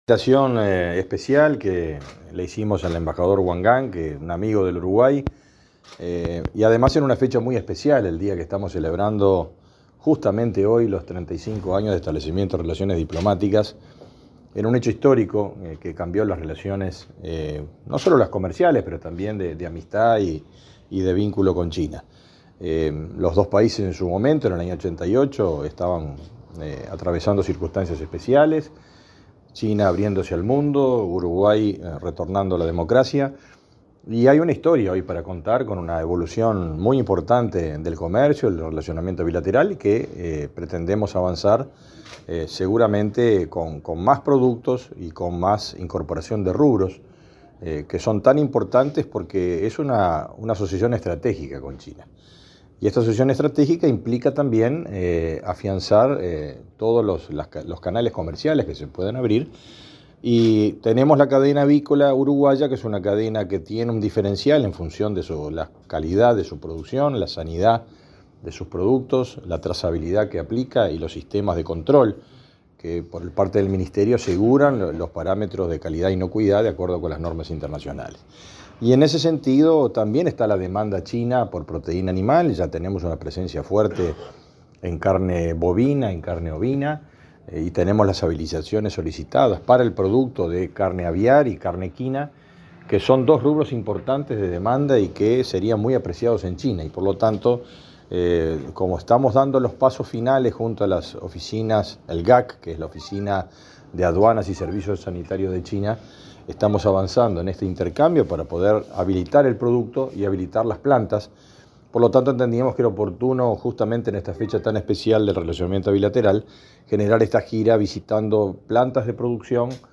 Declaraciones del ministro de Ganadería y el embajador de China en Uruguay
El ministro de Ganadería, Fernando Mattos, y el embajador de la República Popular de China, Wang Gang, dialogaron con la prensa durante una recorrida